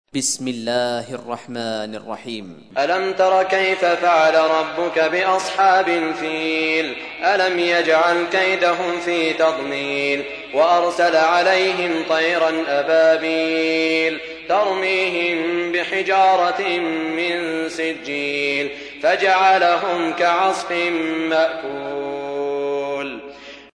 سورة الفيل | القارئ سعود الشريم